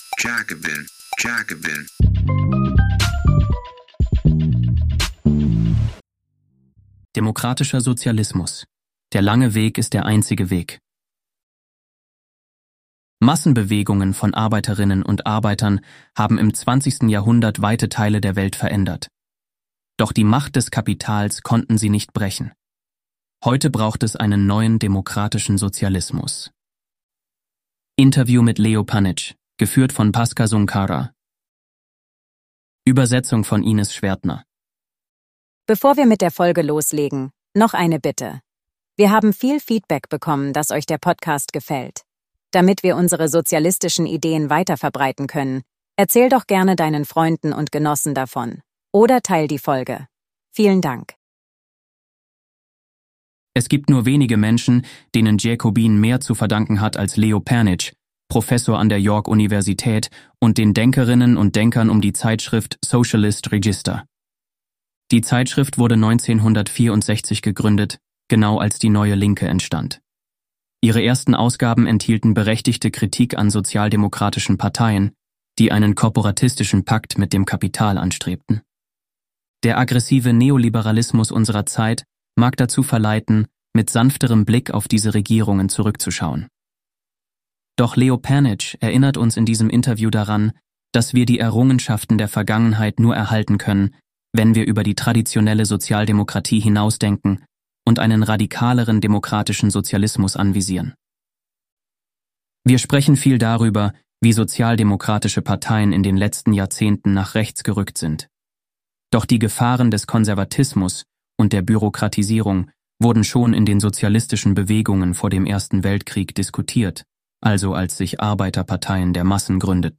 Seitdem gibt es täglich die besten JACOBIN Artikel im Audioformat.